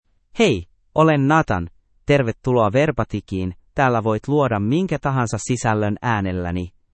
Nathan — Male Finnish AI voice
Nathan is a male AI voice for Finnish (Finland).
Voice sample
Listen to Nathan's male Finnish voice.
Nathan delivers clear pronunciation with authentic Finland Finnish intonation, making your content sound professionally produced.